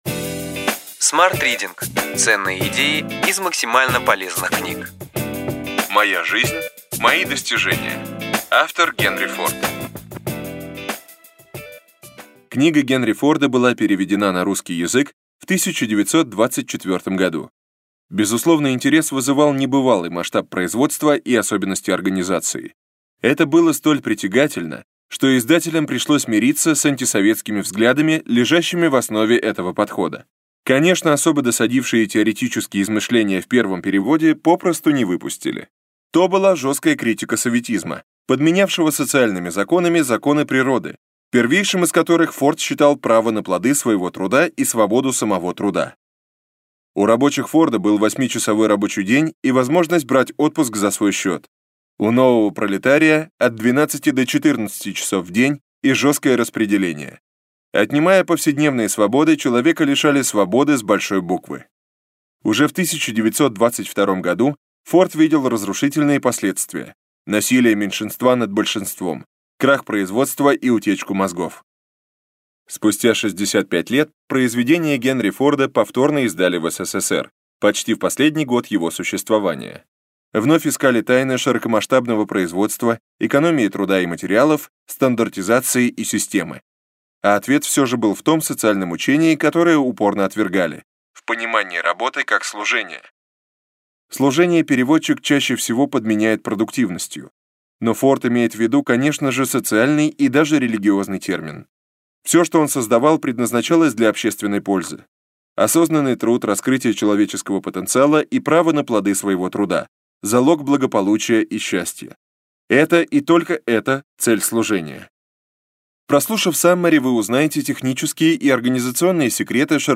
Аудиокнига Ключевые идеи книги: Моя жизнь, мои достижения. Генри Форд | Библиотека аудиокниг